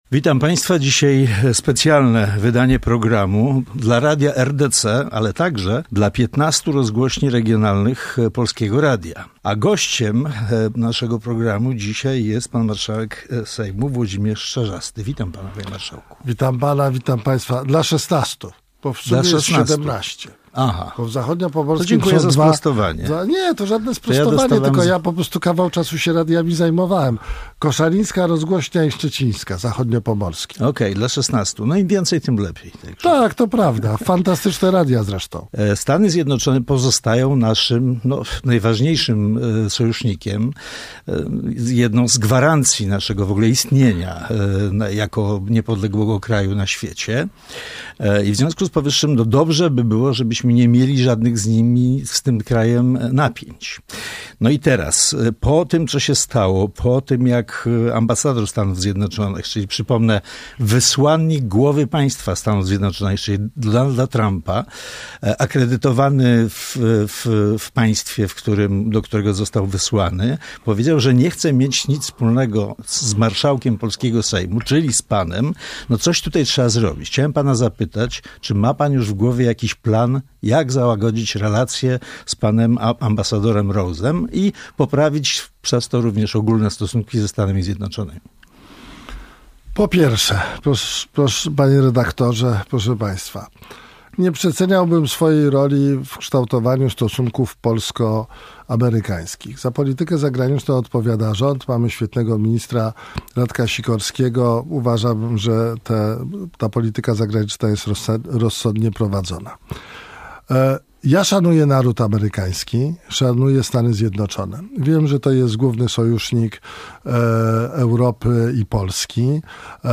Autor: , Tytuł: Wywiad Włodzimierz Czarzasty